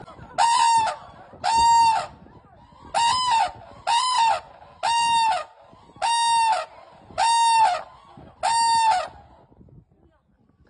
丹顶鹤打鸣声